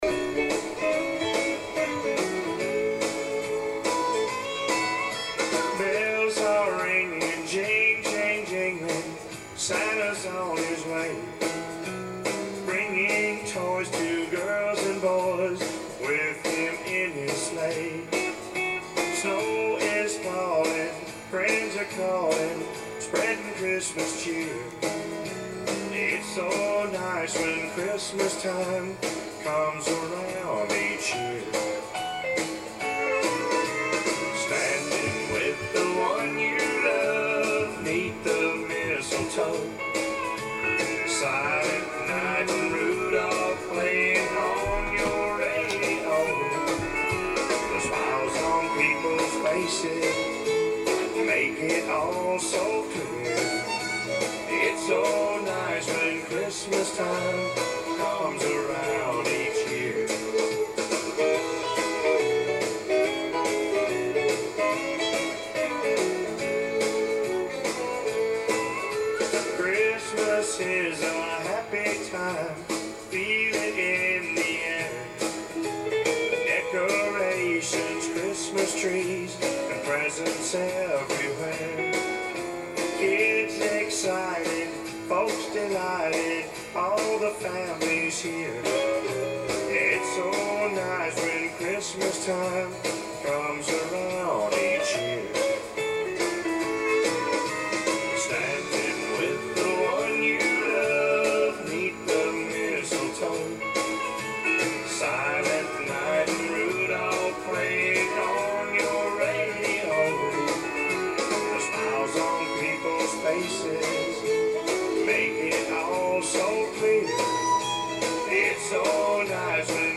Country Christmas